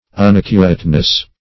Unaccurateness \Un*ac"cu*rate*ness\, n.